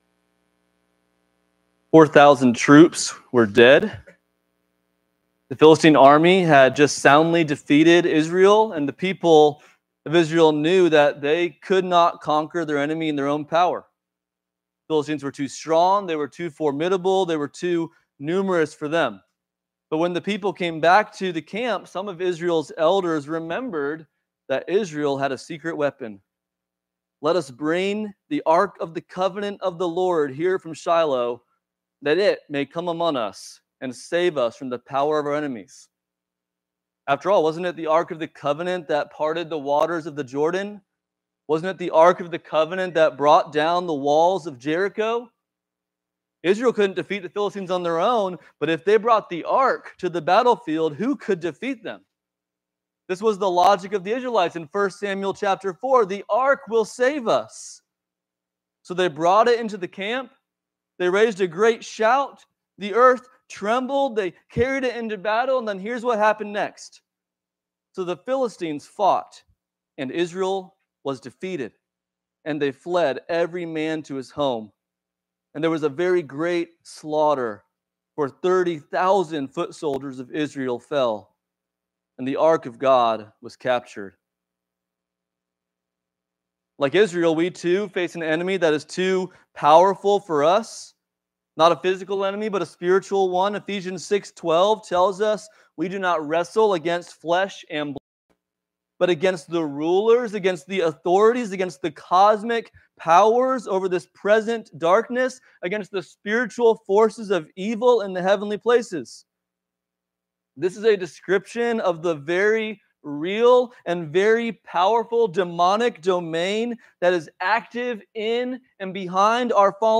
Sunday-Worship-at-Redeemer-Church-1⧸19.mp3